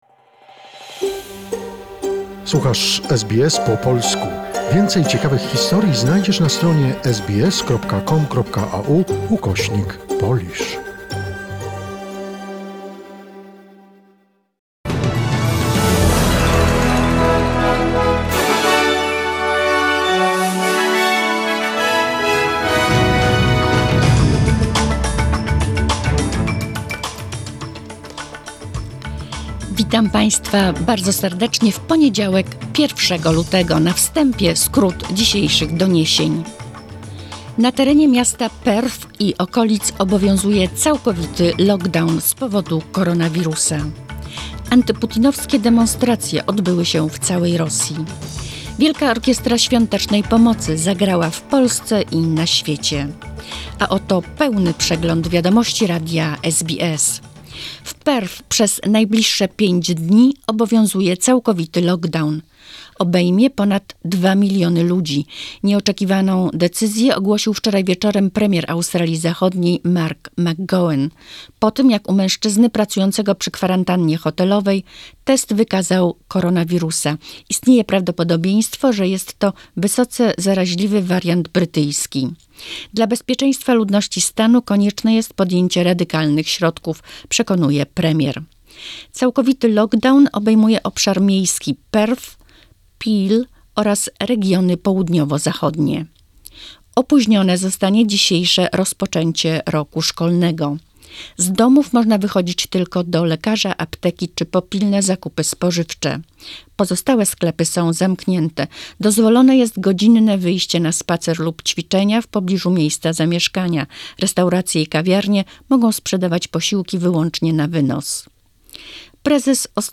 Wiadomości SBS, 1 luty 2021 r.